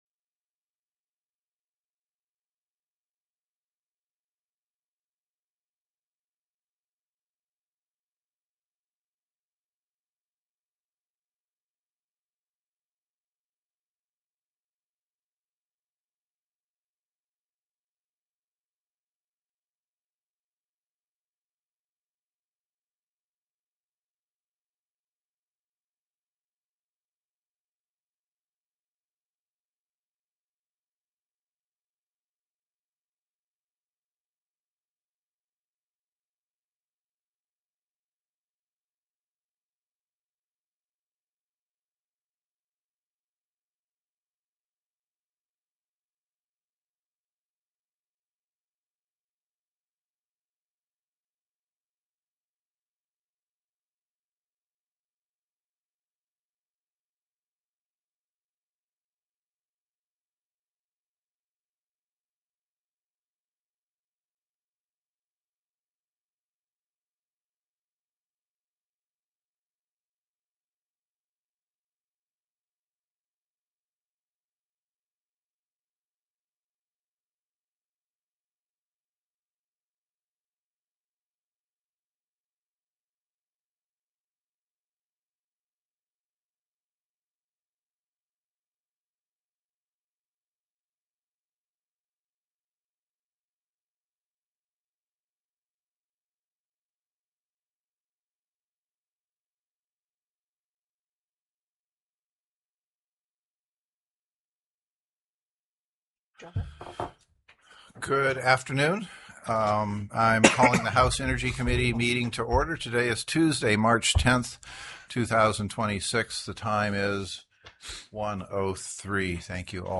The audio recordings are captured by our records offices as the official record of the meeting and will have more accurate timestamps.
1:04:51 PM CURTIS THAYER, Executive Director, Alaska Energy Authority (AEA), gave a presentation titled "Alaska Energy Authority," via PowerPoint [hard copy included in the committee packet]. He began the presentation on slide 2, which gave a broad overview of the services and programs offered by the Alaska Energy Authority (AEA). 1:08:33 PM REPRESENTATIVE JOHNSON asked Mr. Thayer to describe the role of the AEA in power cost equalization (PCE) and further questioned how the PCE